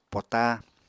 [pota] n. paper